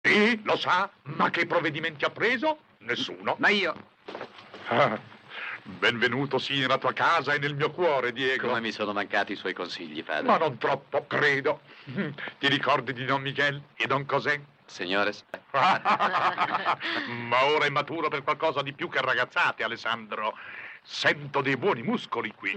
voce di Franco Coop nel film "Il segno di Zorro", in cui doppia Eugene Pallette.
Attore distinto e raffinato, nel campo del doppiaggio si � distinto per una voce particolarmente nasale.